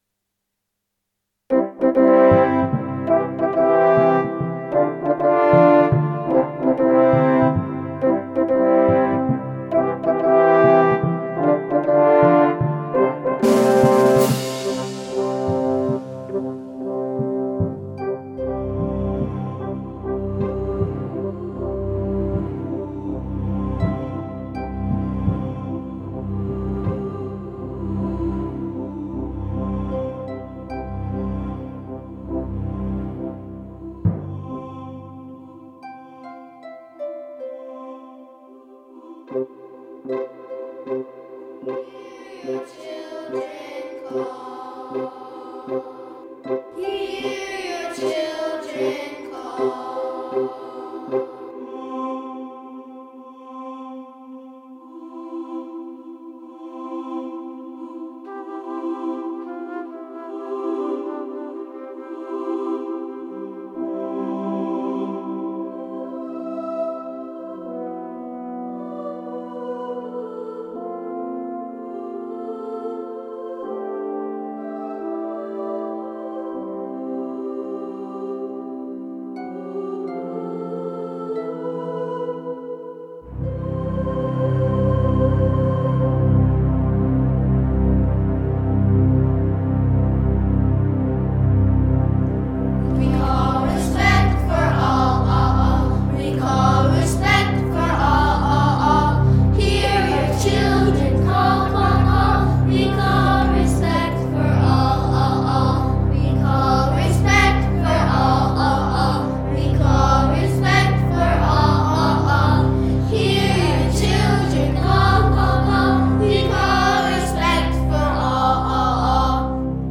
This short piece for orchestra and choir includes a chorus of children calling for greater respect among adults.